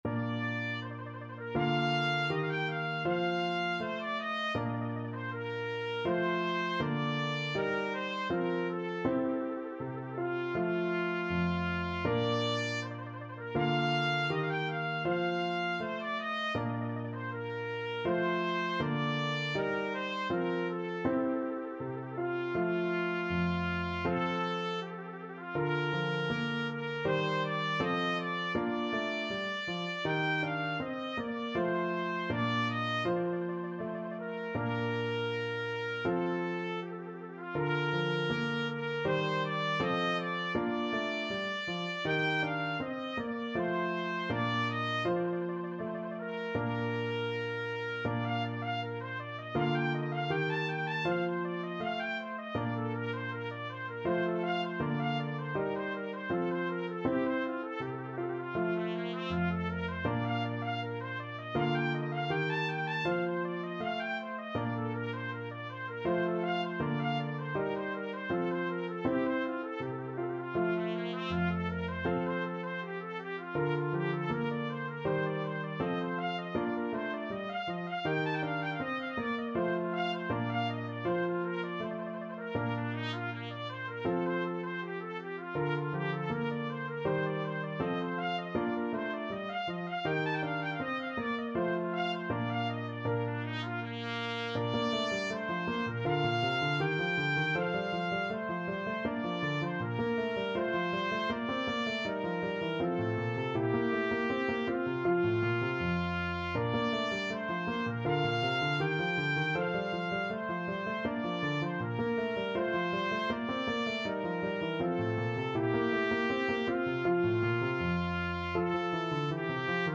Allegretto =80
A4-Bb6
4/4 (View more 4/4 Music)
Trumpet  (View more Advanced Trumpet Music)
Classical (View more Classical Trumpet Music)